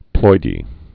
(ploidē)